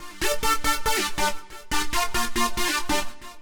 Index of /musicradar/future-rave-samples/140bpm
FR_Arpune_140-D.wav